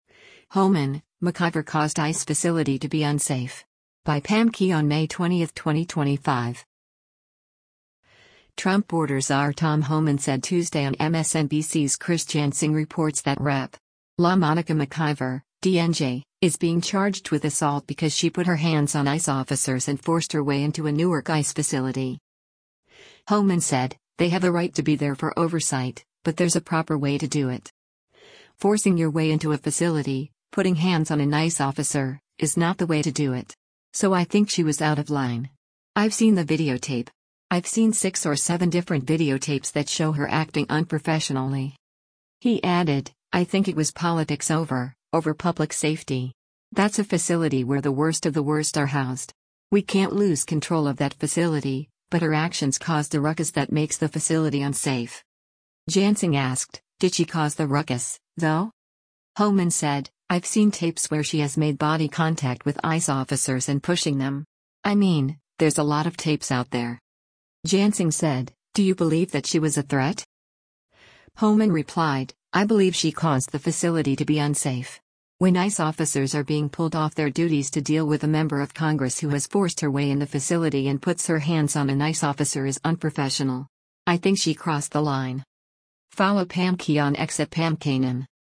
Trump border Czar Tom Homan said Tuesday on MSNBC’s “Chris Jansing Reports” that Rep. LaMonica McIver (D-NJ) is being charged with assault because she put her hands on ICE officers and “forced” her way into a Newark ICE facility.
Jansing asked, “Did she cause the ruckus, though?”